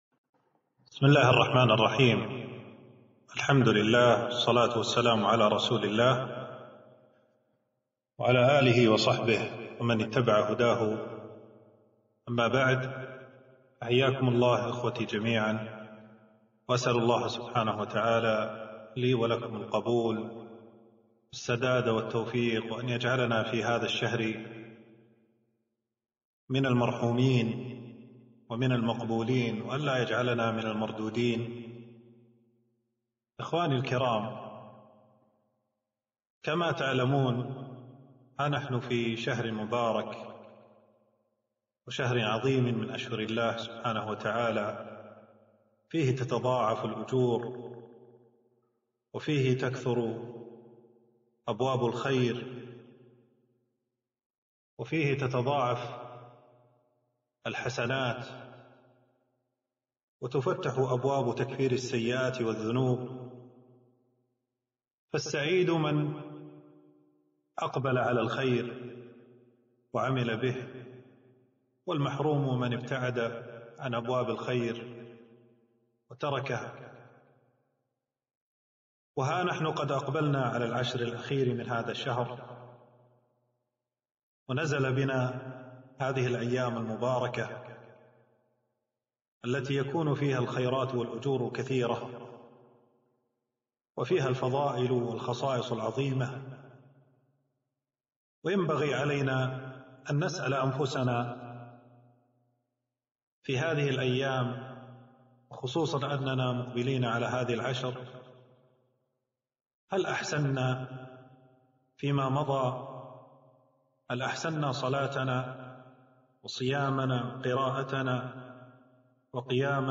محاضرة - العشرُ الأواخِرِ فضْلُها والحرصُ عليها - دروس الكويت